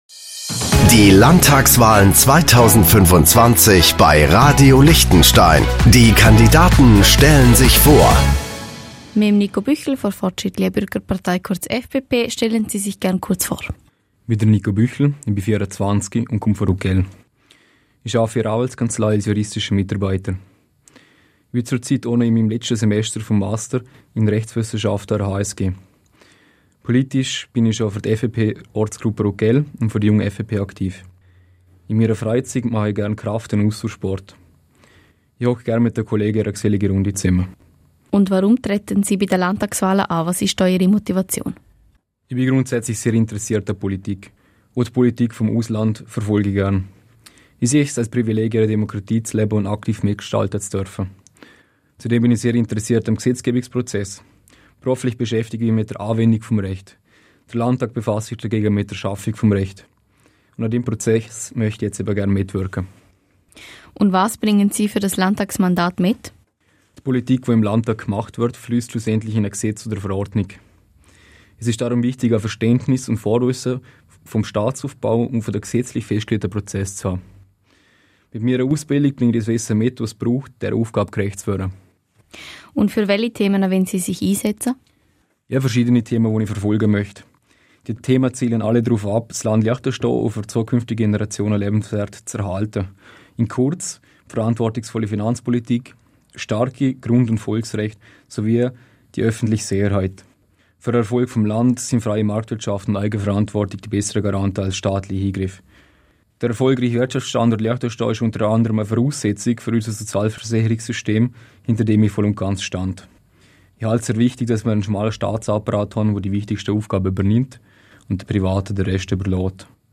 Landtagskandidat